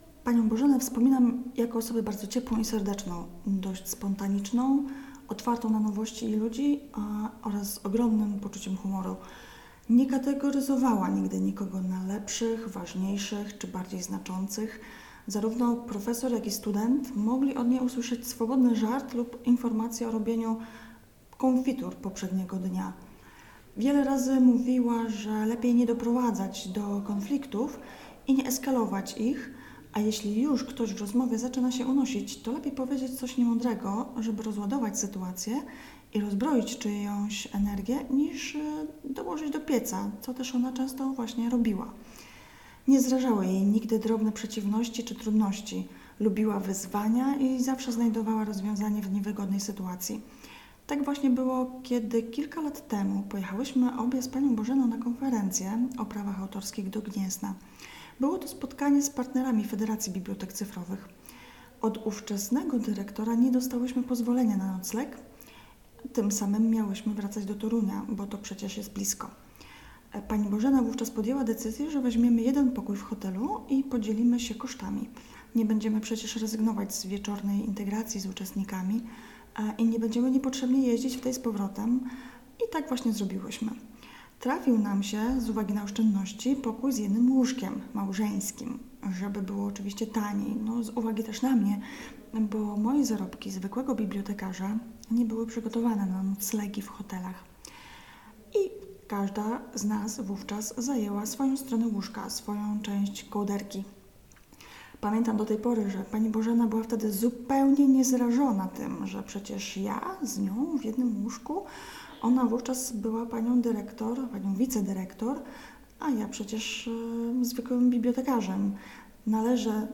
Historia mówiona